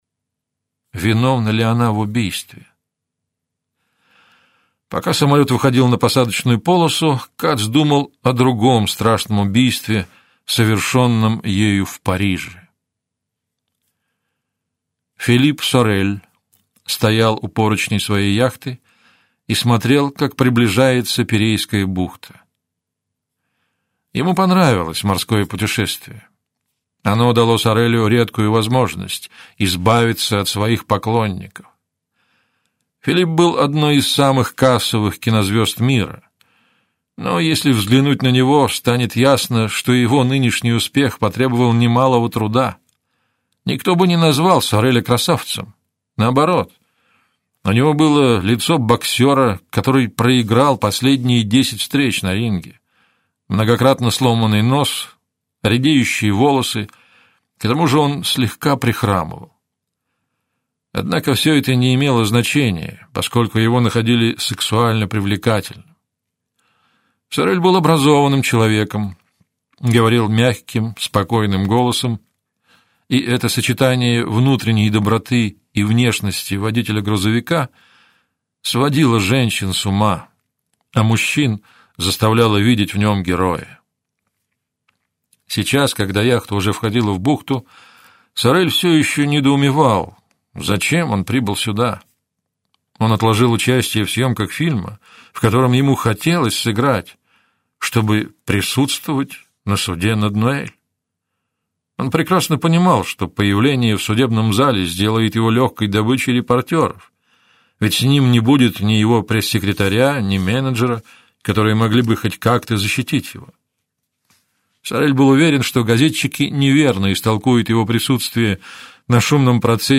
Аудиокнига Оборотная сторона полуночи - купить, скачать и слушать онлайн | КнигоПоиск